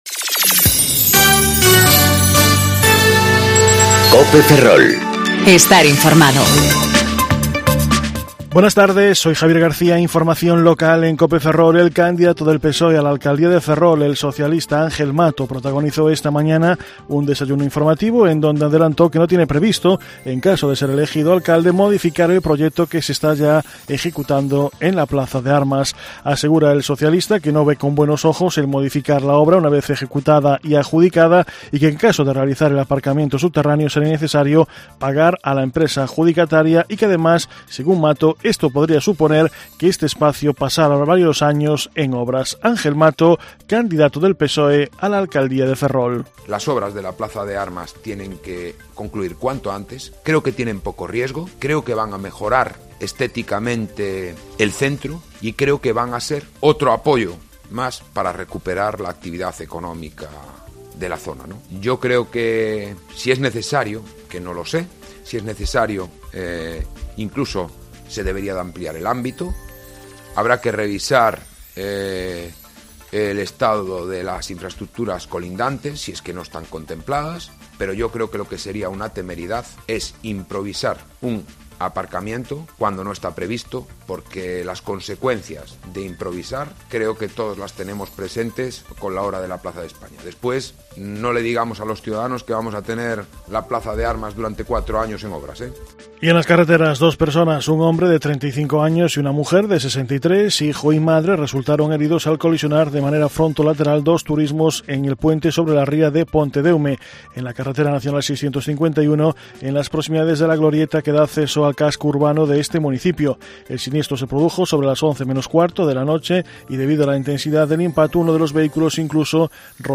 Informativo Mediodía Cope Ferrol 13/05/2019 (De 14.20 a 14.30 horas)